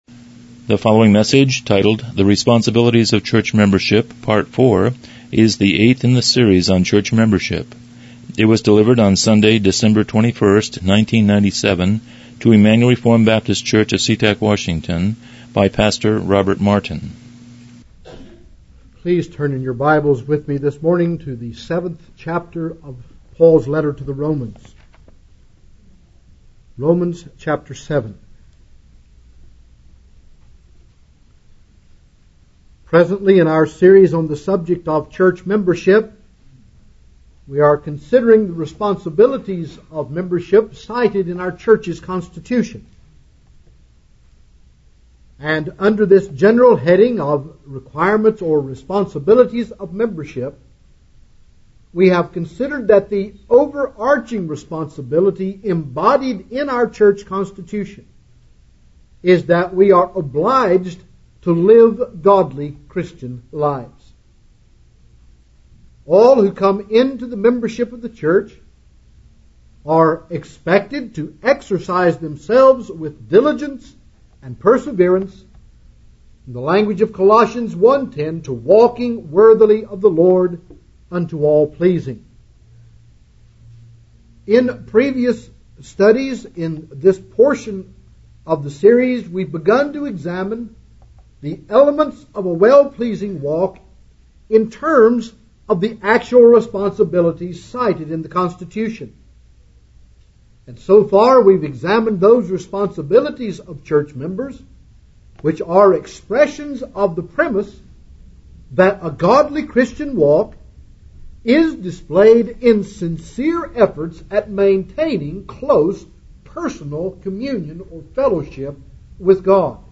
Church Membership Service Type: Morning Worship « 07 Responsibilities of